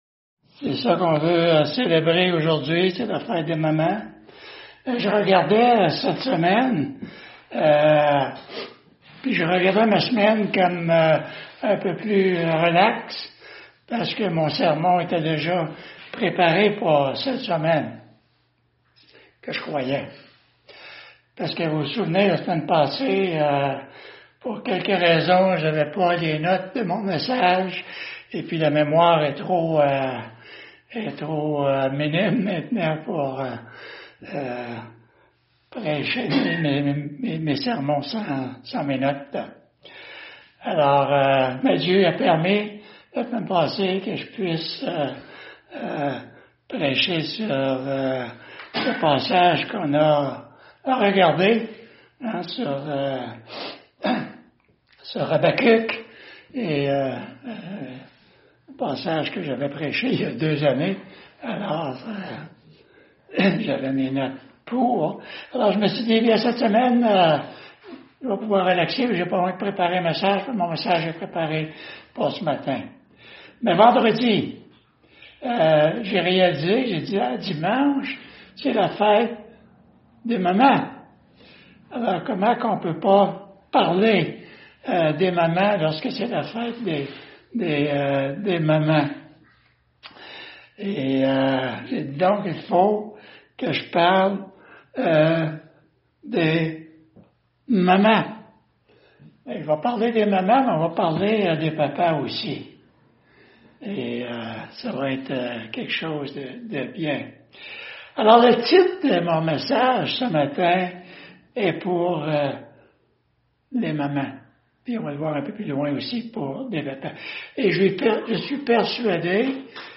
Église Baptiste de Mont-Tremblant | Sermons
Fetedesmeres.mp3